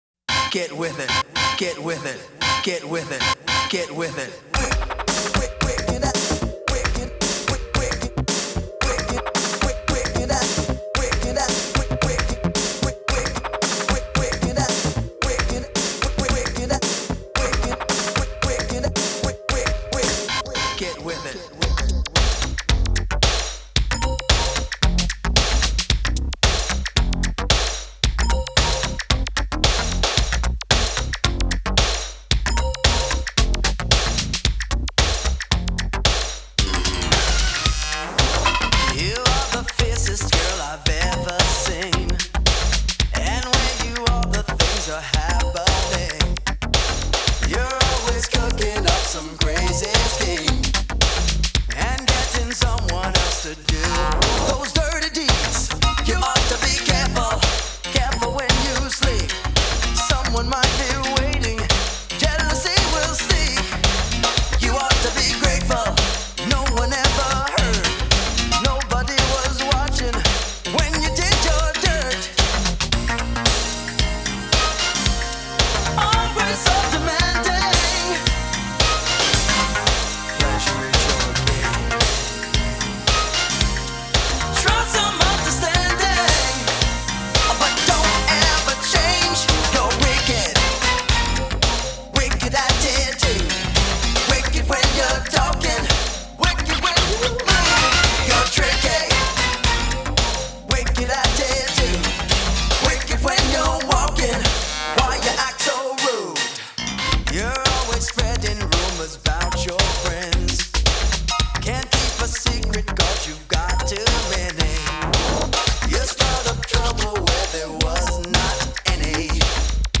late 80s electrofunk is my jam 💿